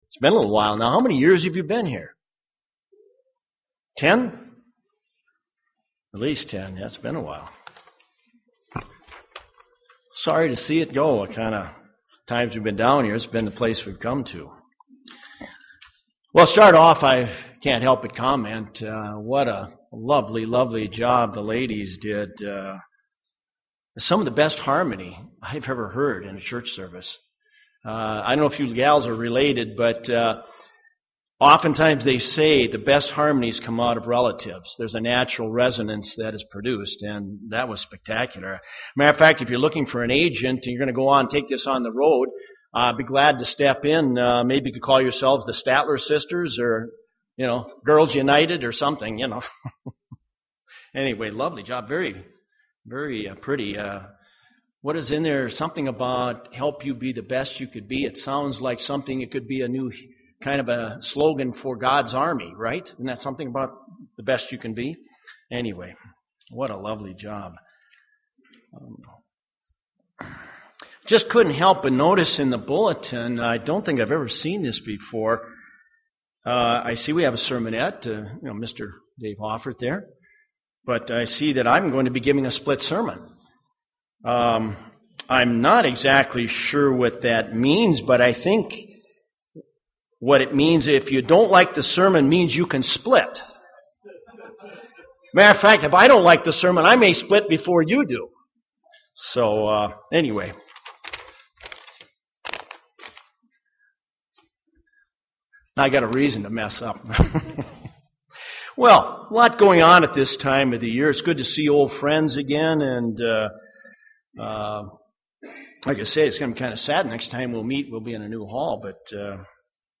Matthew 5:43-48 Romans 12:17-21 Proverbs 25:21-22 UCG Sermon Studying the bible?